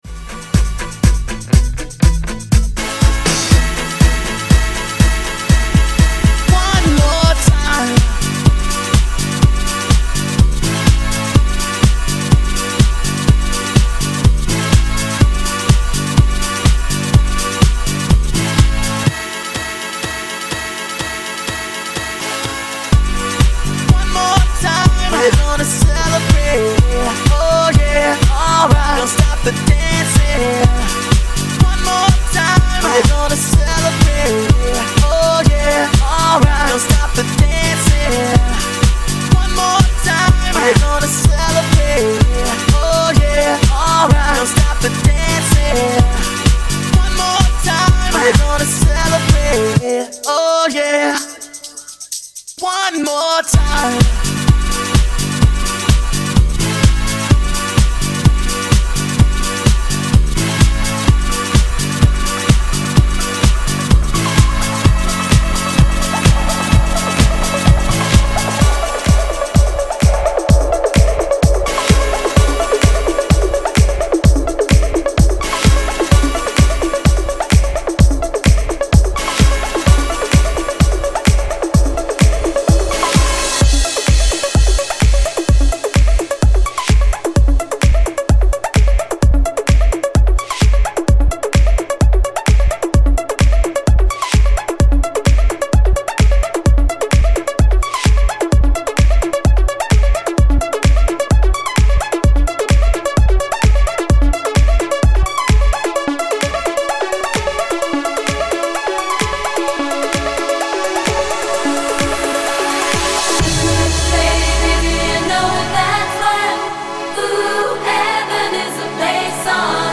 A live EDM and house mix